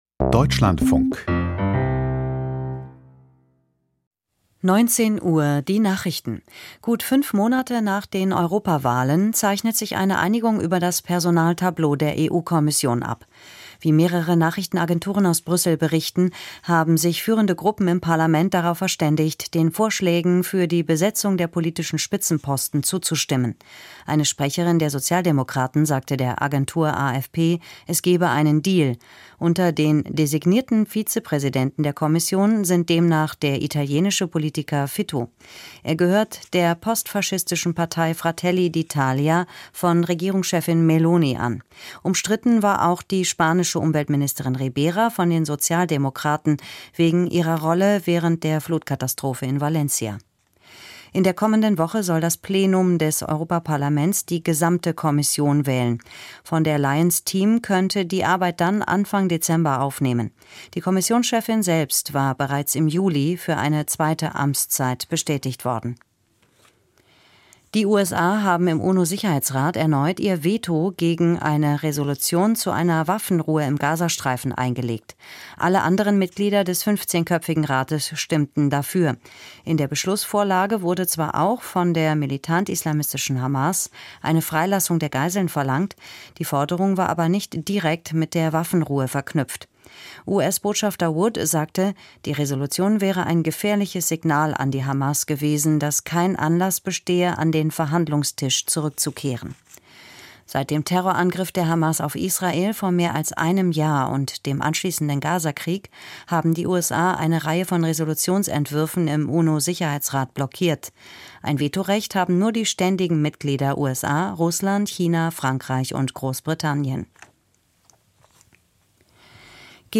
Kommentar zur hybriden, russischen Kriegsführung: Putin zieht die Schrauben an - 20.11.2024